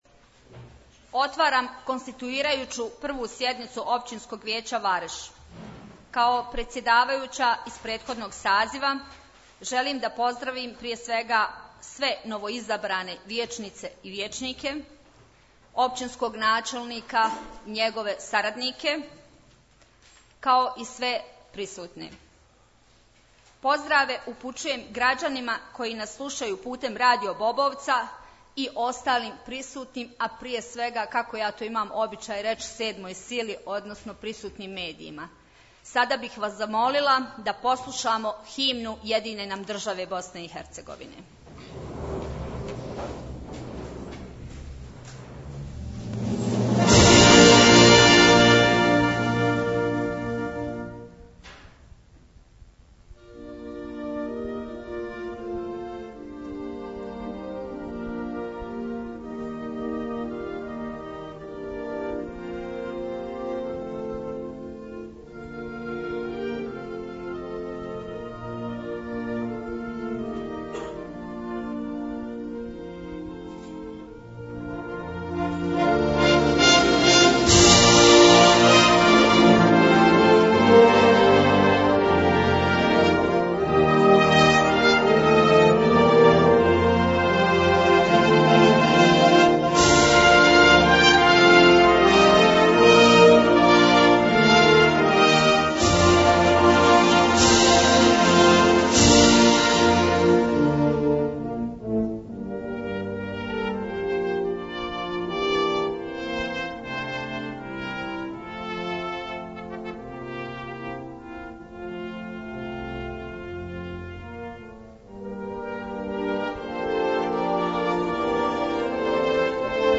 U dvorani Općine Vareš održana je konstituirajuća sjednica Općinskog vijeća Vareš u novom sazivu na osnovu lokalnih izbora održanih u listopadu 2024. godine.